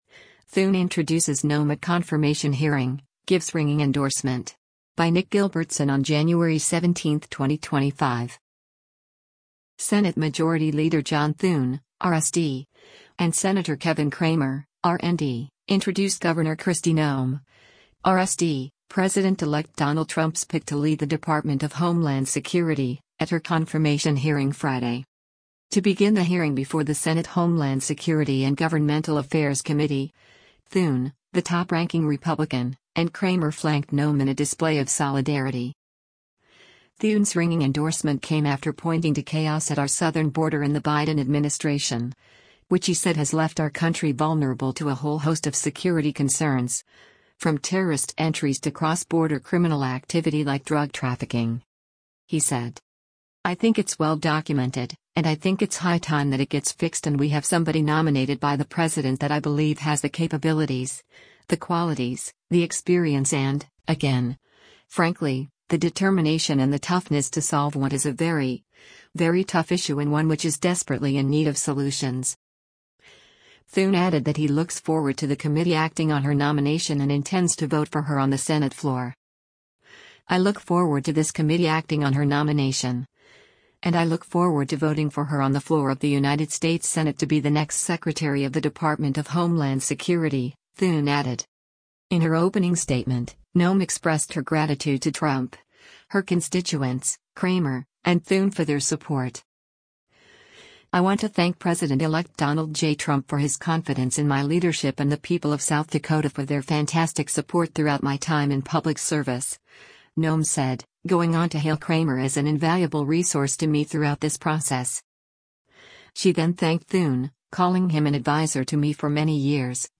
Thune Introduces Noem at Confirmation Hearing, Gives Ringing Endorsement
Senate Majority Leader John Thune (R-SD) and Sen. Kevin Cramer (R-ND) introduced Gov. Kristi Noem (R-SD), President-elect Donald Trump’s pick to lead the Department of Homeland Security, at her confirmation hearing Friday.